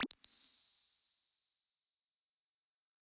generic-hover-soft.wav